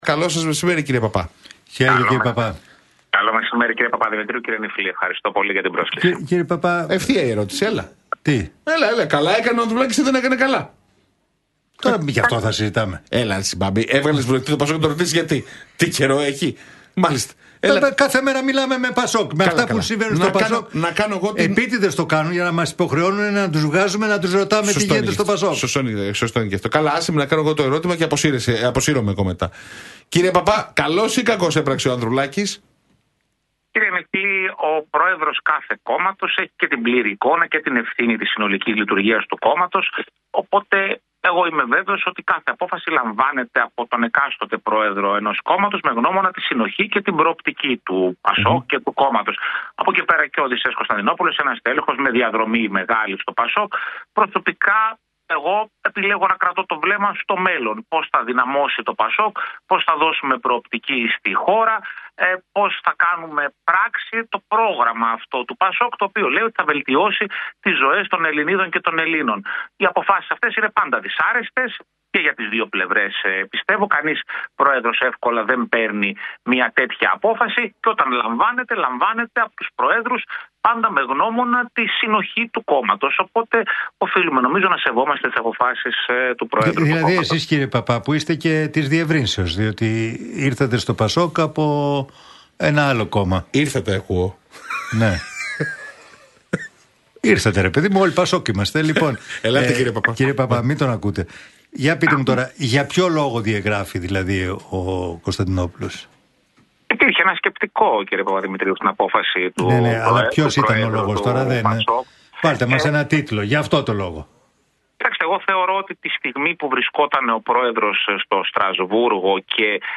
Πέτρος Παππάς στον Realfm 97,8: για Κωνσταντινόπουλο: Δεν ήταν ο κατάλληλος χρόνος για κριτική - Οφείλουμε να σεβόμαστε τις αποφάσεις του προέδρου